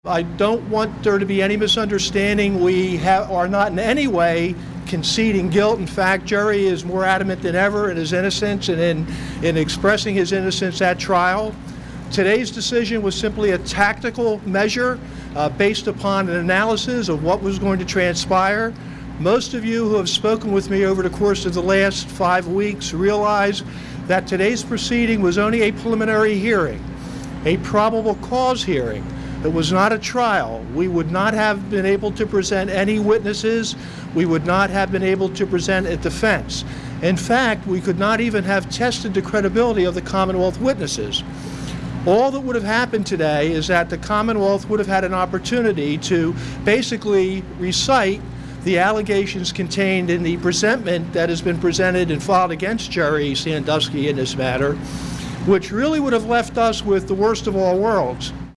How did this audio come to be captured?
took to the Centre County Courthouse steps, in Bellefonte, to explain the surprise move: